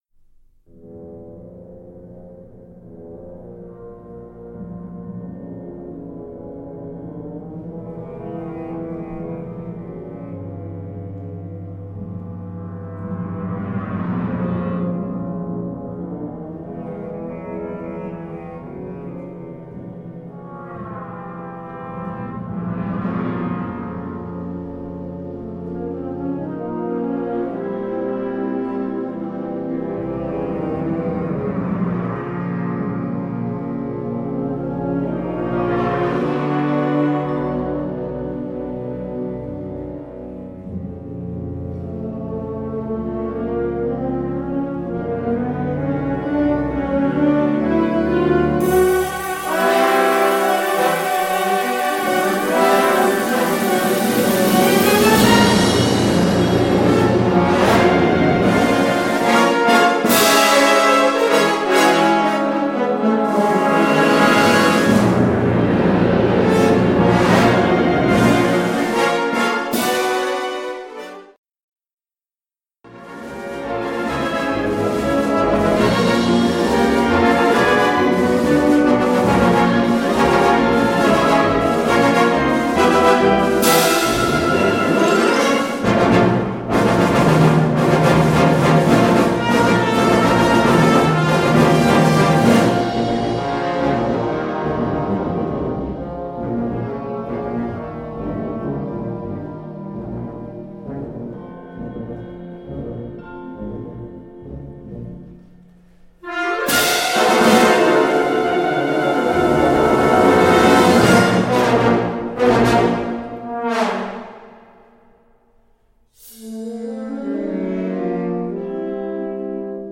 Unterkategorie Zeitgenössische Bläsermusik (1945-heute)
Besetzung Ha (Blasorchester)
Einige Schlagwerk- und Malleteffekte (auch Vibraphon).